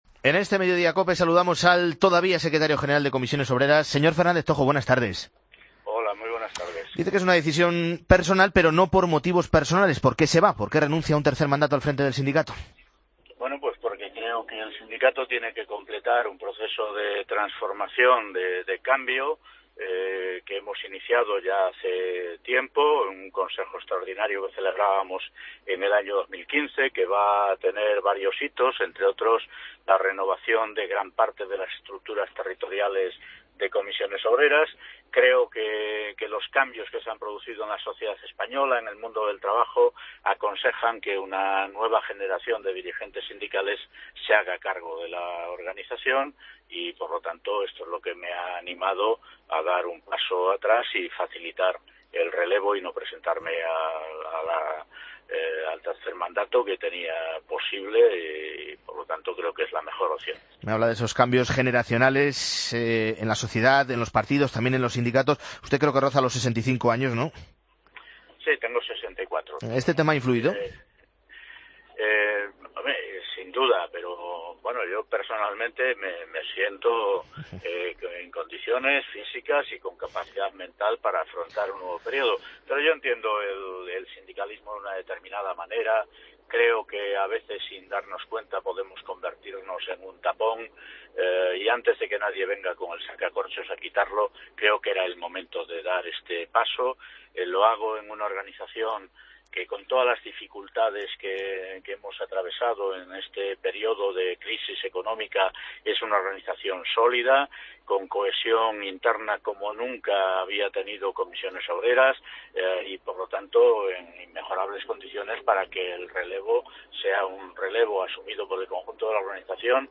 AUDIO: Escucha a Ignacio Fernández Toxo en el informativo 'Mediodía' del fin de semana